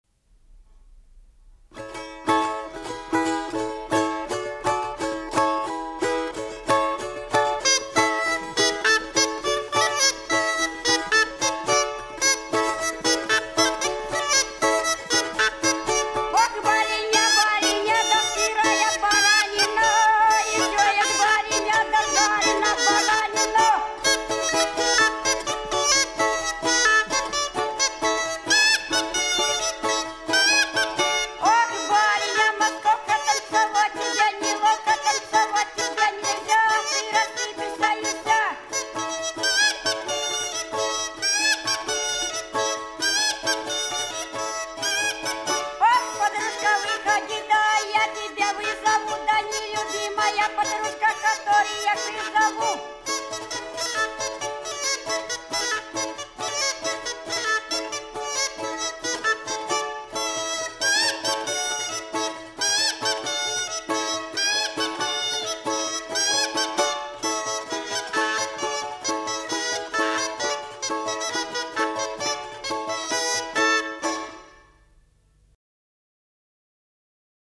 Голоса уходящего века (Курское село Илёк) Барыня (балалайки, рожок, пение, частушки)